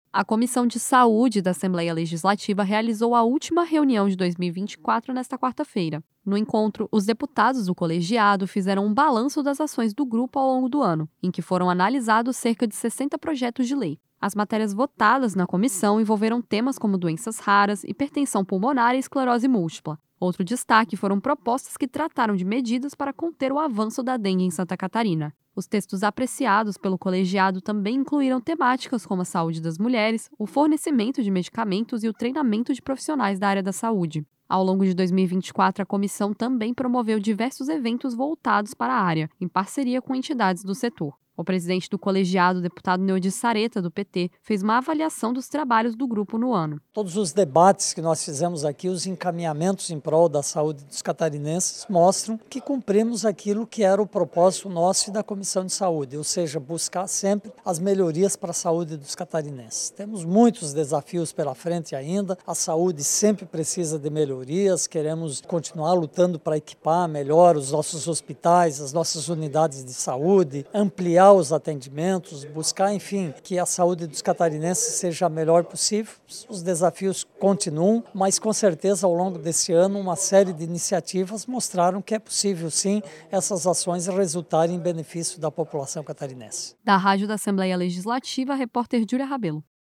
Entrevista com:
- deputado Neodi Saretta (PT), presidente da Comissão de Saúde.